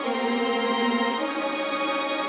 Index of /musicradar/gangster-sting-samples/105bpm Loops
GS_Viols_105-CD#.wav